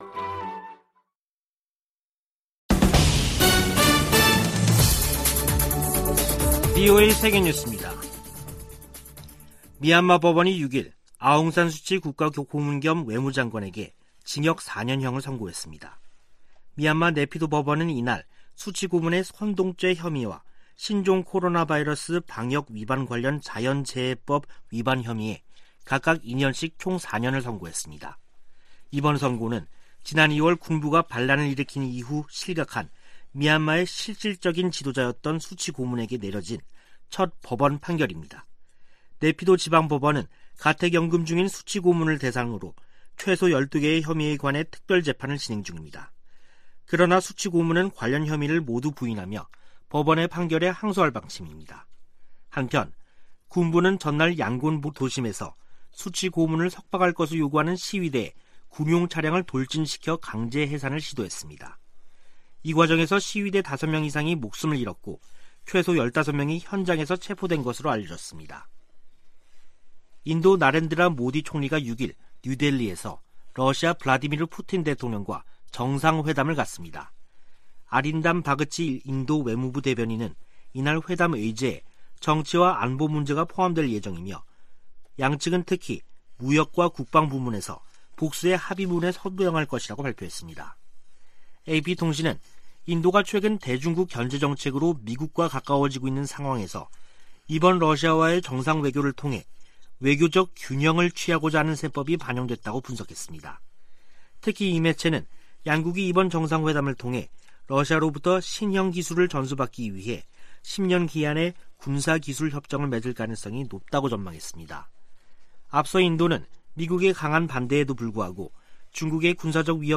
VOA 한국어 간판 뉴스 프로그램 '뉴스 투데이', 2021년 12월 6일 3부 방송입니다. 중국이 한반도 종전선언 추진에 지지 의사를 밝혔지만 북한은 연일 미국을 비난하며 냉담한 태도를 보이고 있습니다. 유엔이 올해에 이어 내년에도 북한을 인도지원 대상국에서 제외했다고 확인했습니다. 미국 유권자 42%는 조 바이든 행정부 출범 이후 미북 관계가 악화한 것으로 생각한다는 조사 결과가 나왔습니다.